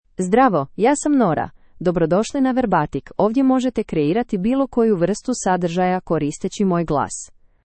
FemaleCroatian (Croatia)
NoraFemale Croatian AI voice
Nora is a female AI voice for Croatian (Croatia).
Voice sample
Listen to Nora's female Croatian voice.